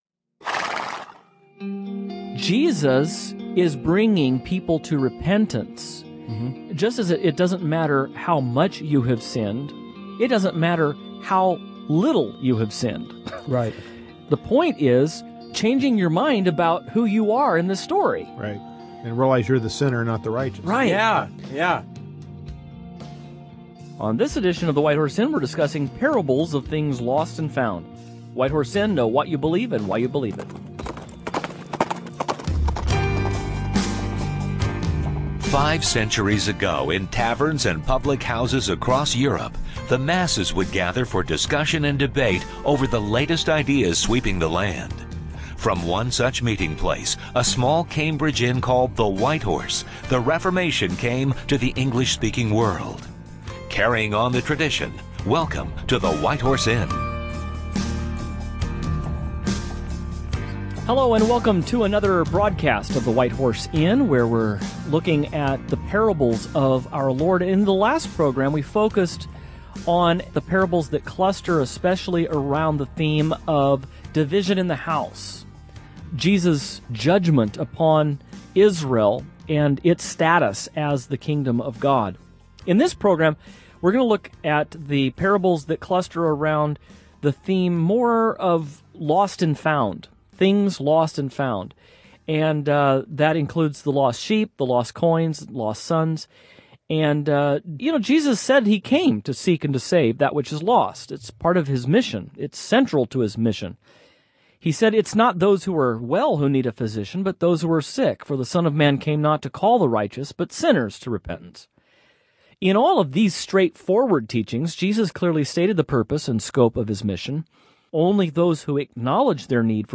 On this program the hosts will discuss the parable of the Prodigal Son and other similar narratives. Through each of these tales, the point Jesus makes is that something lost is suddenly found.